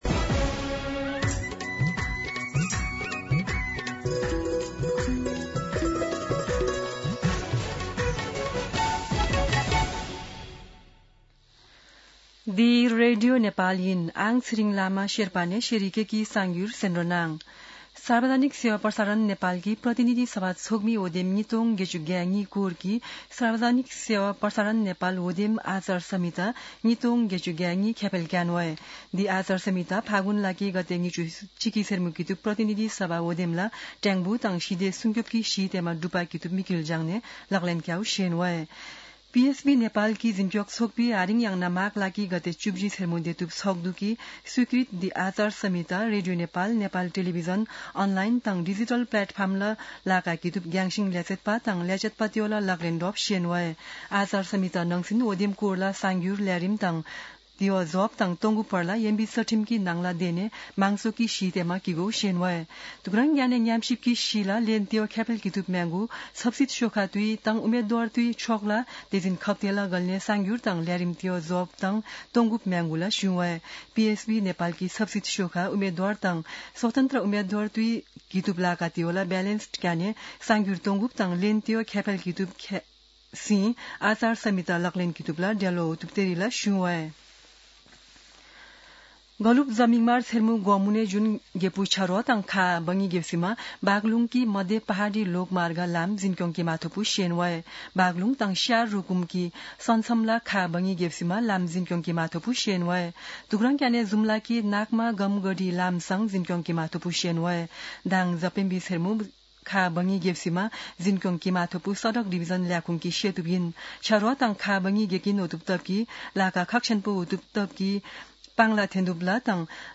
शेर्पा भाषाको समाचार : १४ माघ , २०८२
Sherpa-News-10-14.mp3